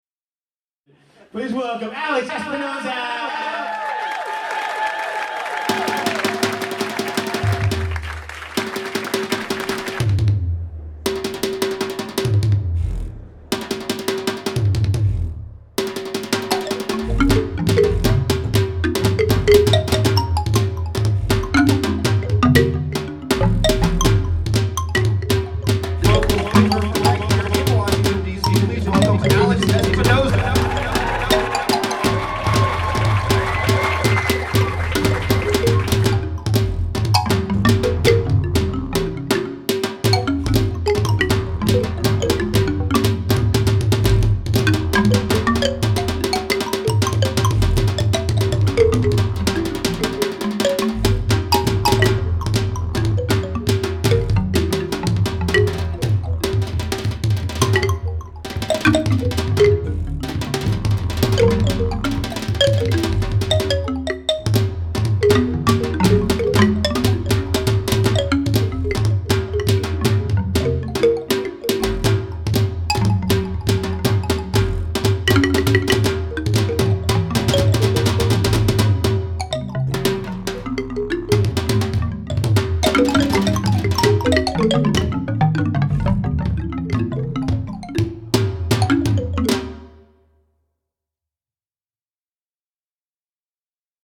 Genre : Experimental